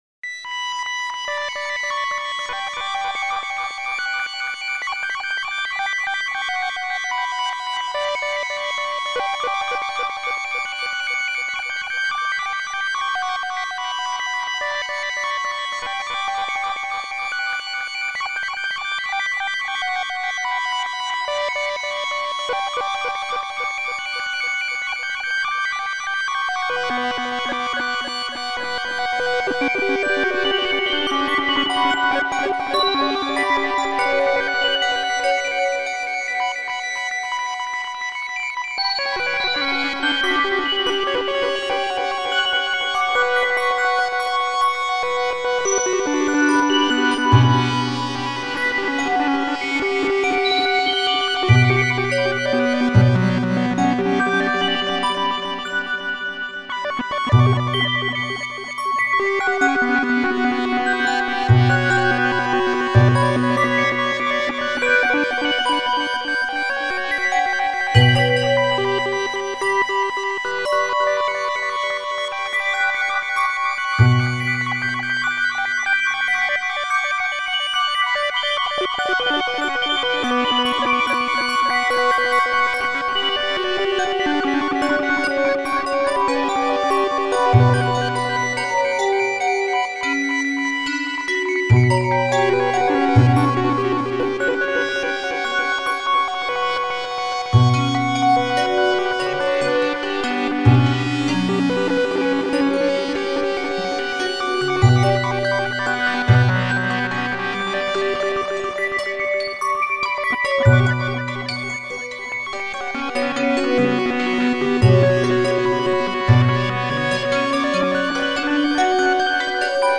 Categorised in: atmospheric, rhythmic